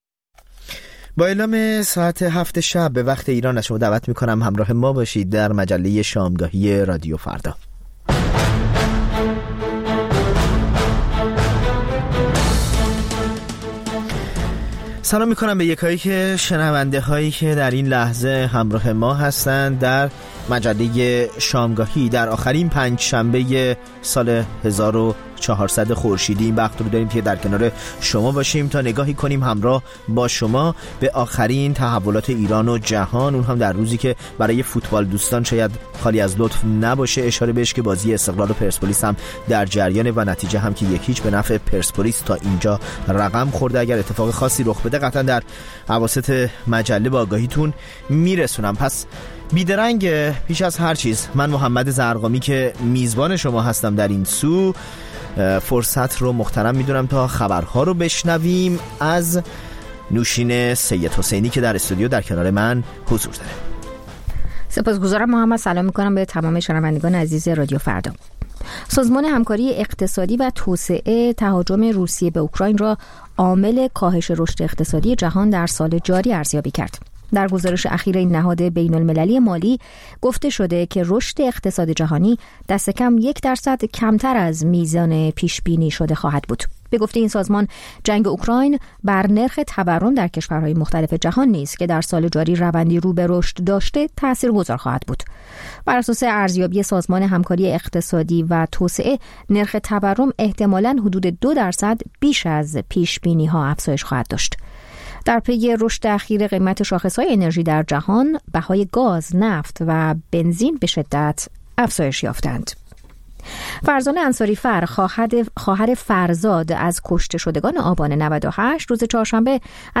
مجموعه‌ای متنوع از آنچه در طول روز در سراسر جهان اتفاق افتاده است. در نیم ساعت اول مجله شامگاهی رادیو فردا، آخرین خبرها و تازه‌ترین گزارش‌های تهیه‌کنندگان رادیو فردا پخش خواهد شد.